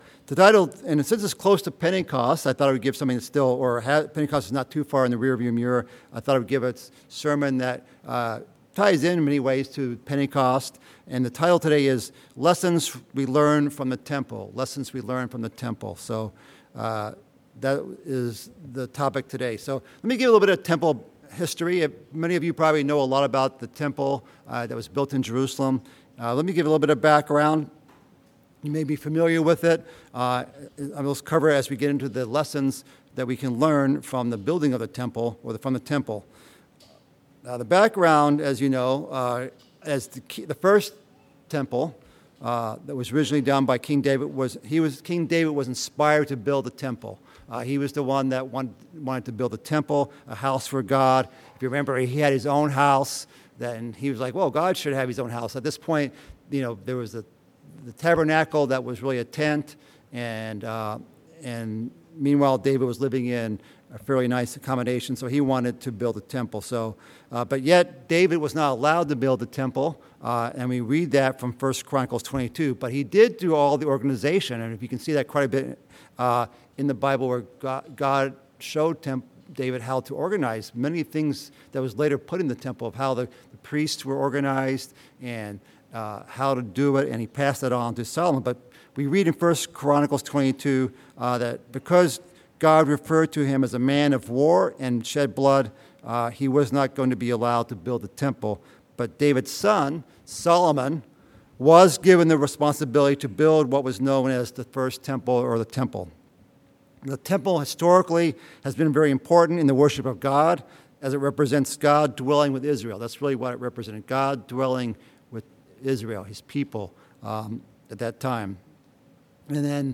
Given in Chicago, IL